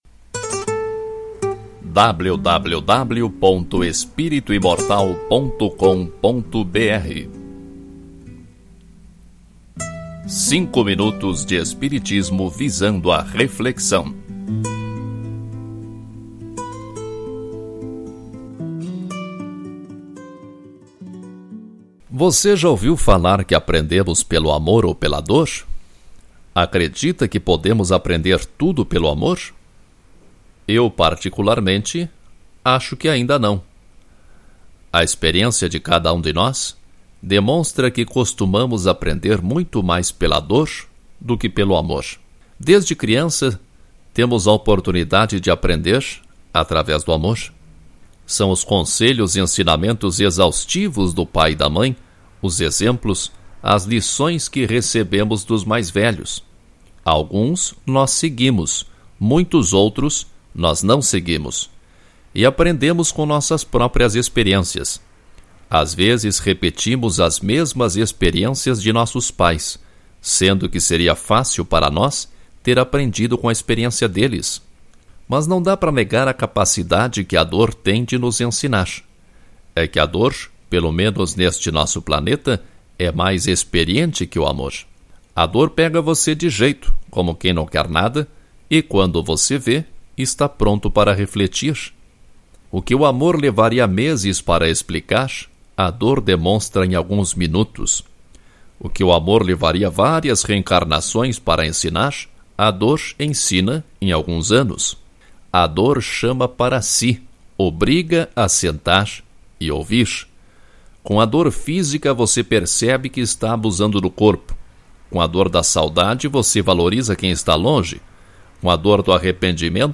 Ouça este artigo na voz do autor